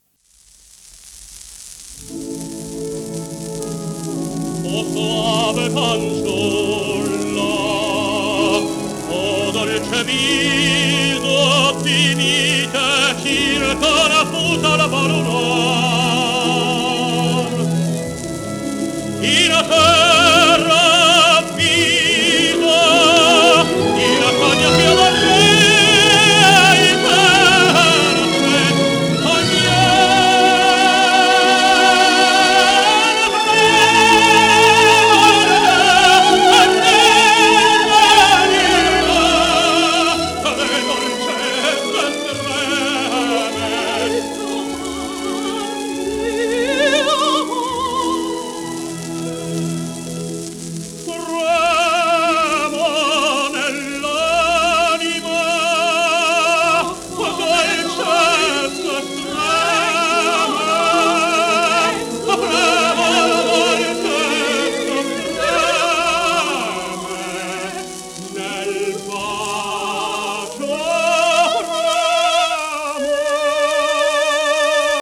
1937年録音